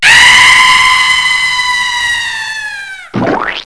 SCREAM.WAV